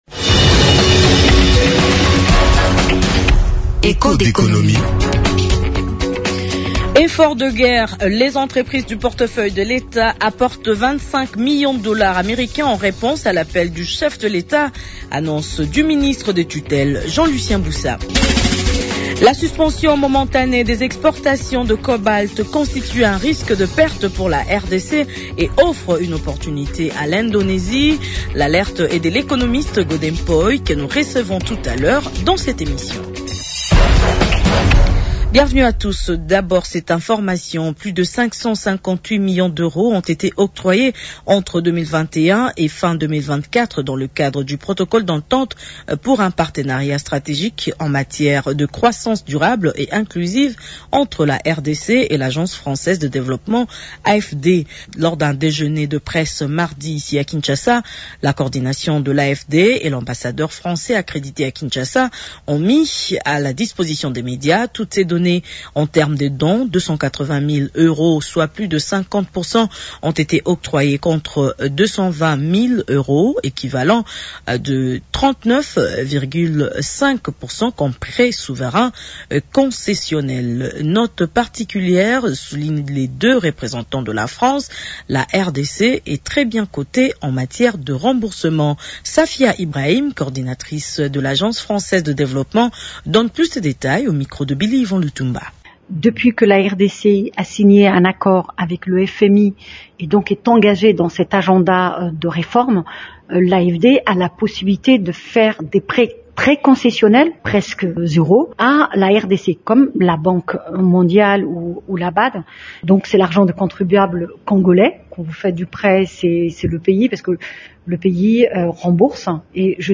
invité du magazine Echos d’économie.